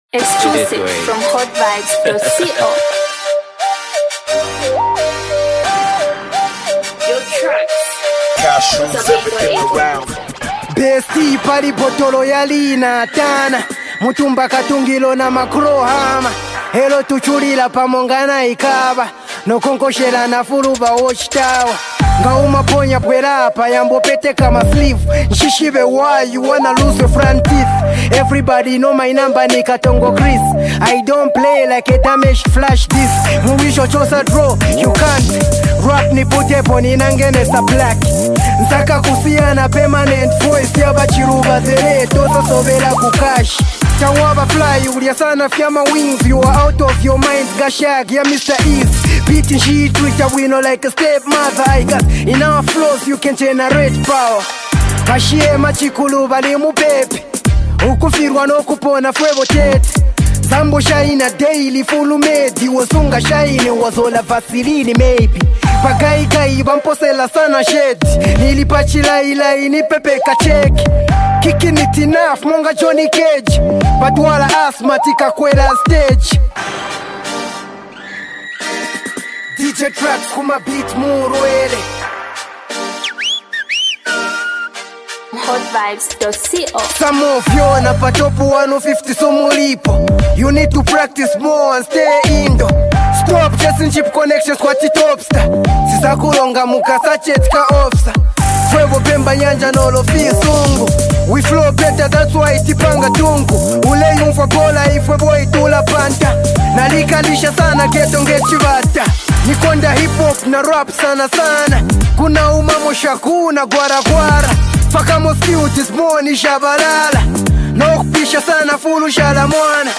bars after bars